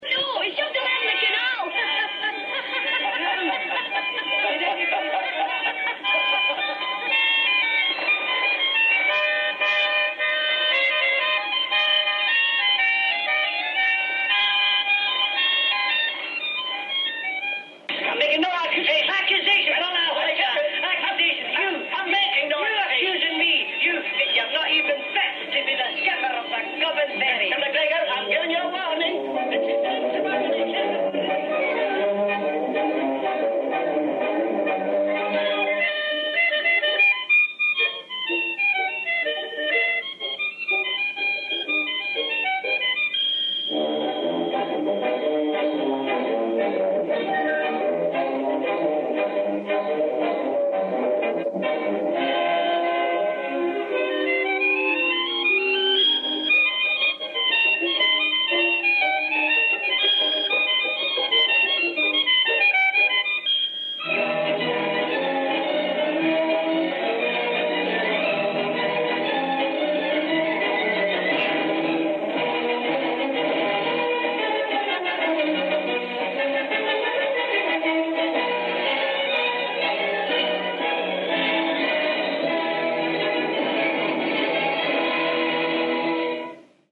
collage of sound from the film
concertina